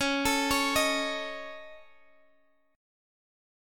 Dbsus2#5 chord